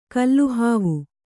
♪ kalluhāvu